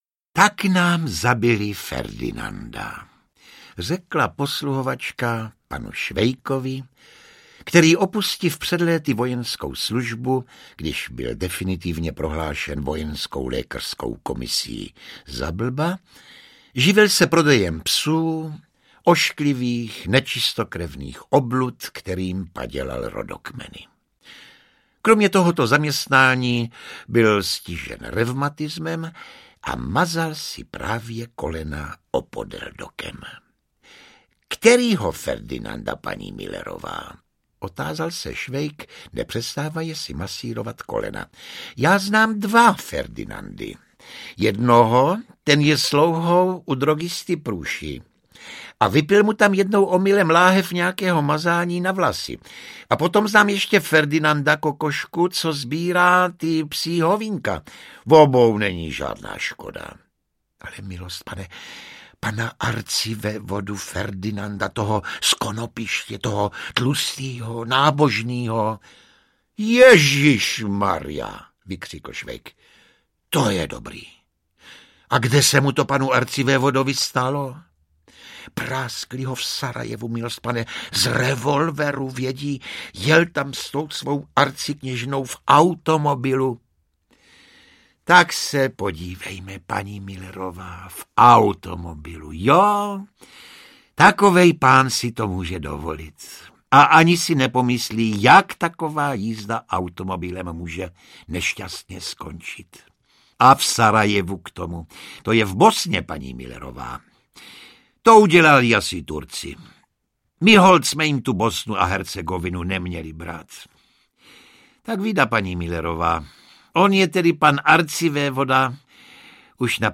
Osudy dobrého vojáka Švejka audiokniha
Ukázka z knihy
Deset excelentních českých herců a mistrů mluveného slova se podělilo v nahrávacím studiu o četbu Osudů dobrého vojáka Švejka. Každý herec originálním a nezaměnitelným způsobem interpretuje jeden úsek románu - jednu Švejkovu epizodu.
• InterpretJosef Somr, Martin Dejdar, Václav Postránecký, Rudolf Hrušínský, Petr Nárožný, Arnošt Goldflam, Pavel Zedníček, Miroslav Donutil, Václav Vydra ml., Bohumil Klepl